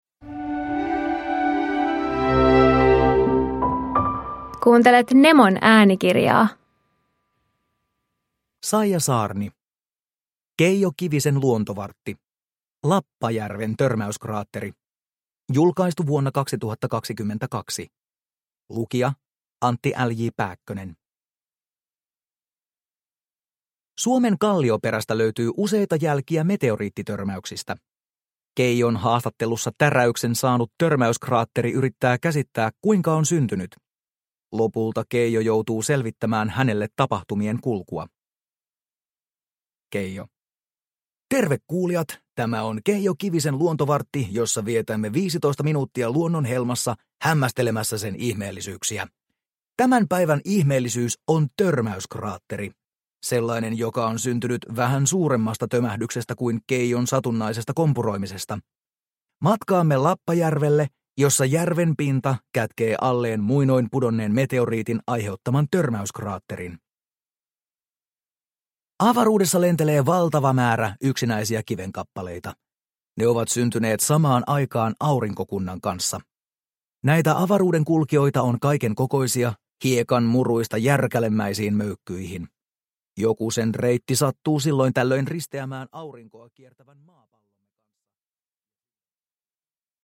Lappajärven törmäyskraatteri – Ljudbok – Laddas ner